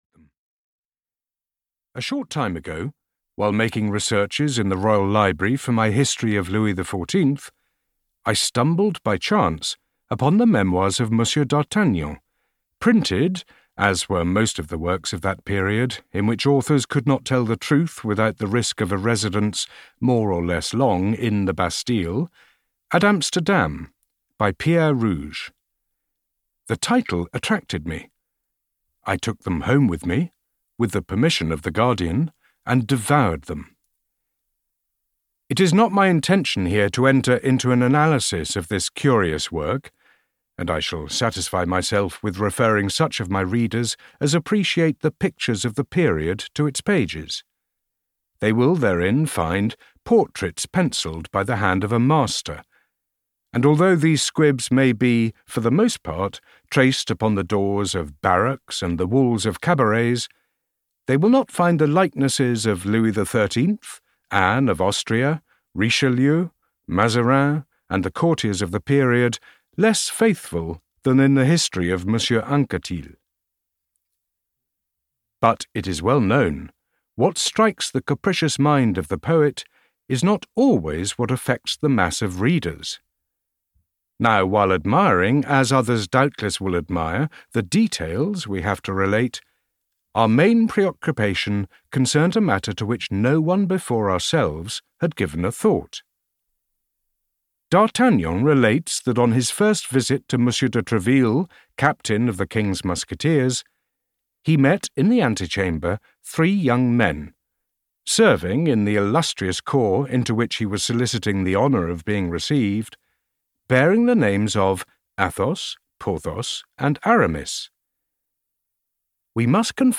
The Three Musketeers I (EN) audiokniha
Ukázka z knihy